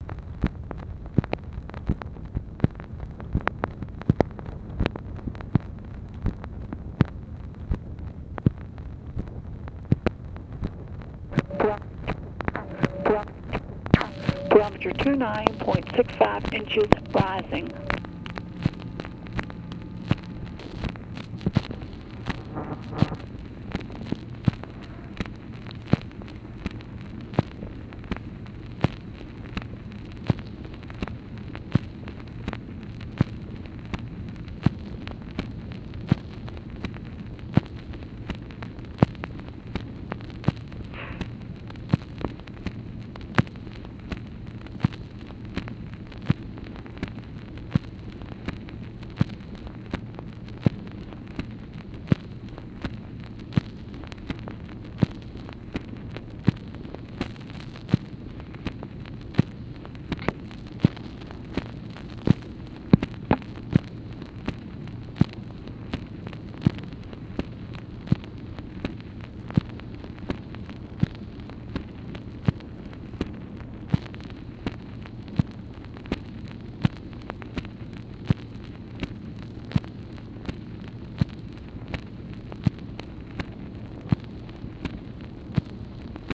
PORTION OF RECORDED WEATHER REPORT